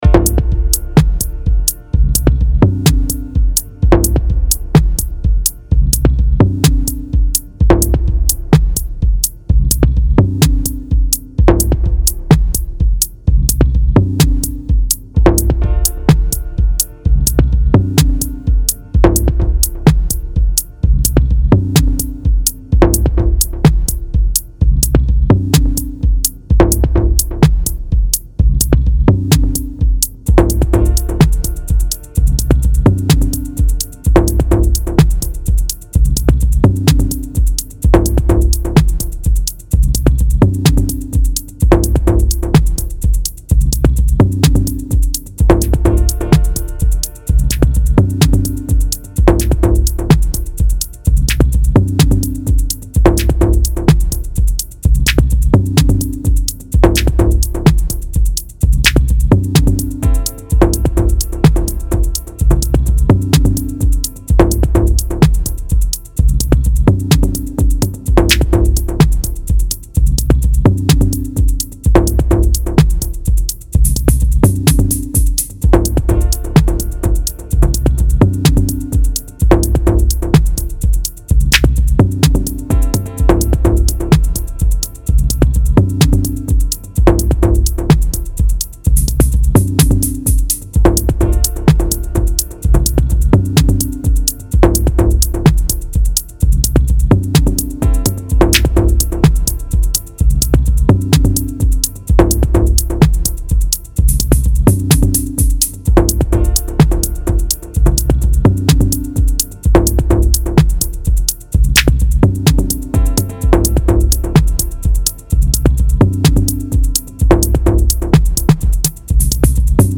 Dub Techno
Hab doch noch ne kleine Variation hinein gebracht, die ich zuvor verworfen hatte.